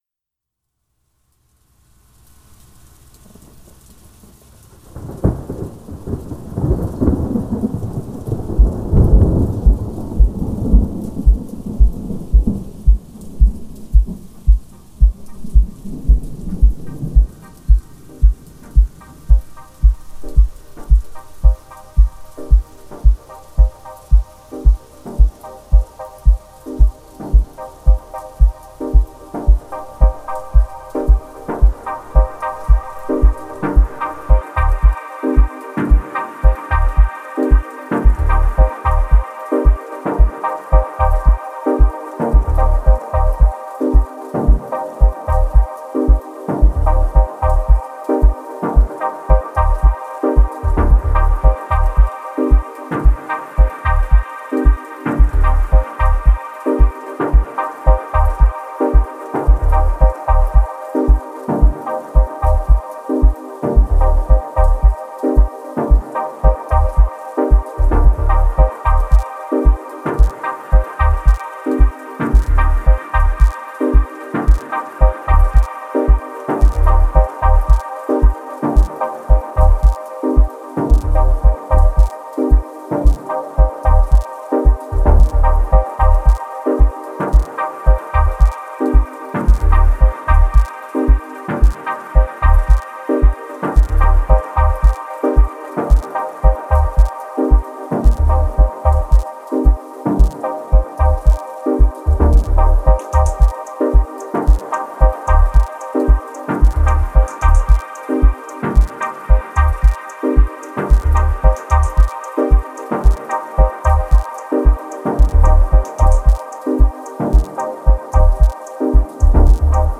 Genre: Deep Techno/Ambient/Dub Techno.